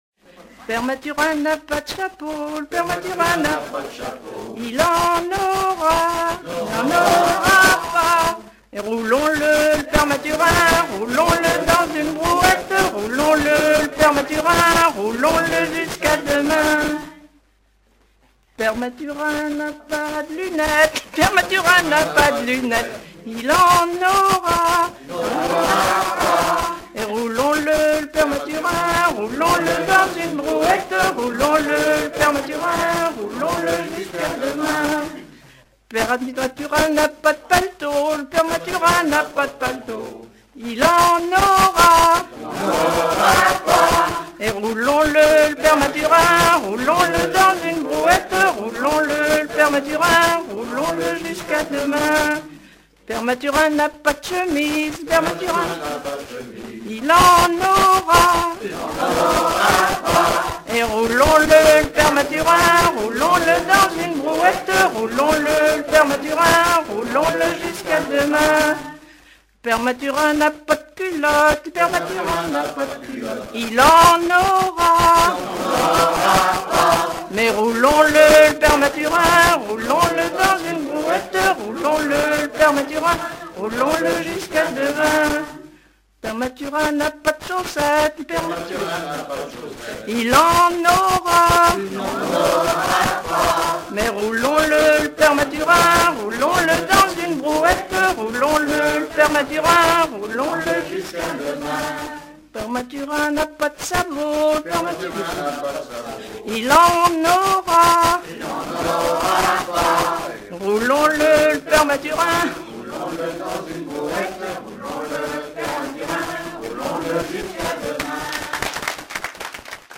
Mervent
rondes enfantines
Genre énumérative